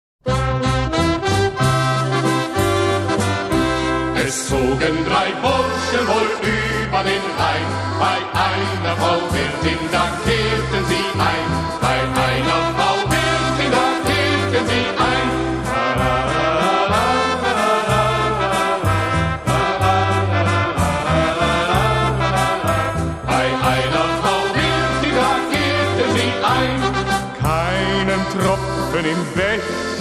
Genre: Around The World - Germany
Music from the Rhine River.